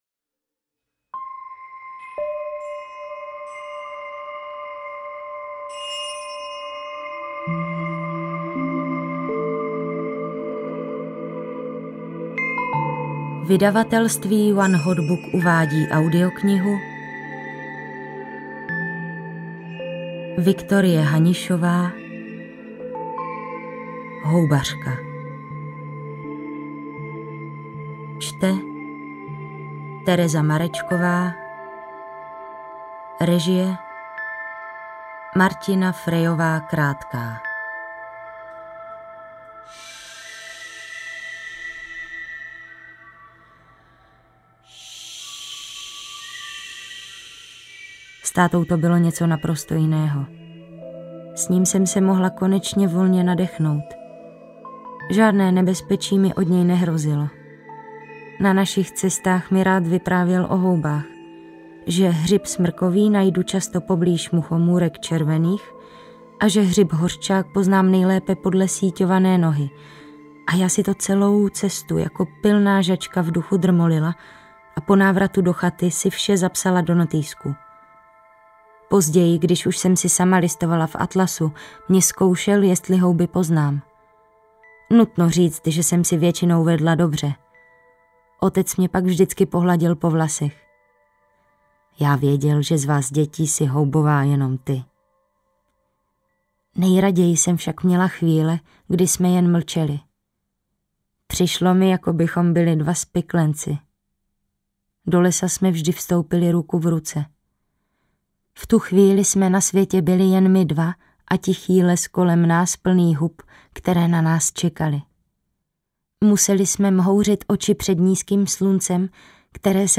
Houbařka audiokniha
Ukázka z knihy